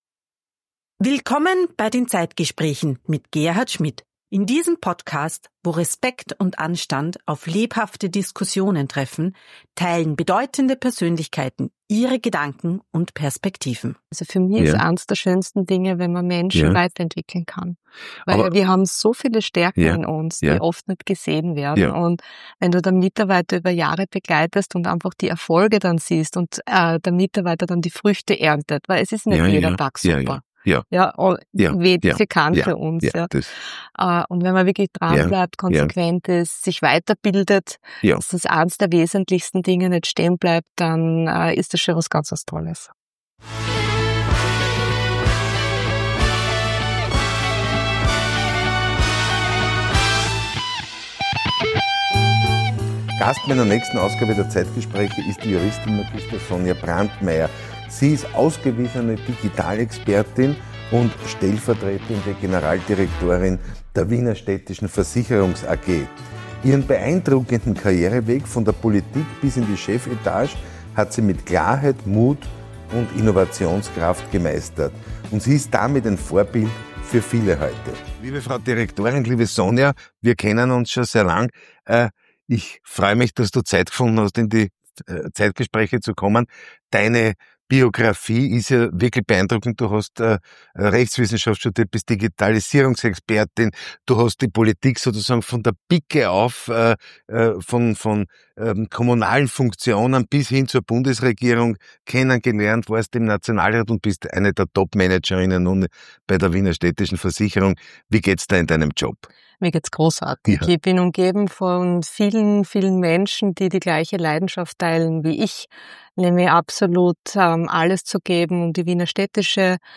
Das Gespräch suchen und finden.